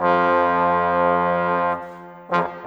Rock-Pop 07 Trombones _ Tuba 03.wav